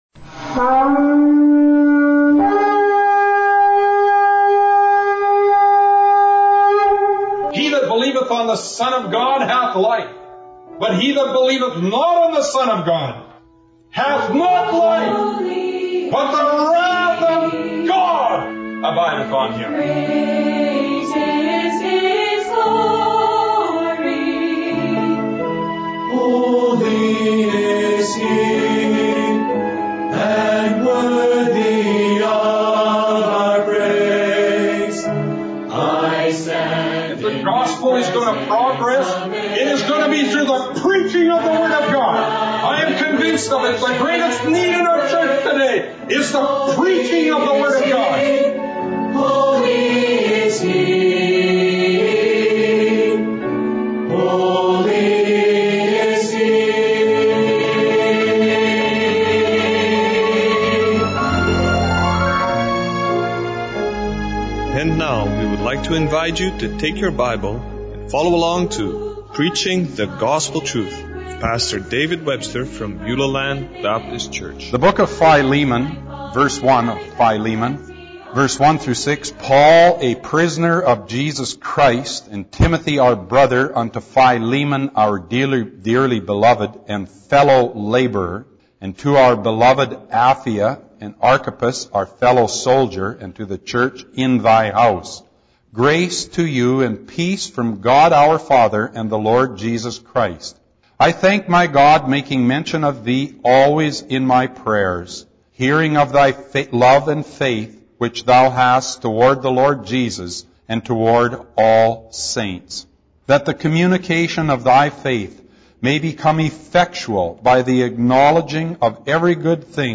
Audio Sermons – Prince Albert Baptist Church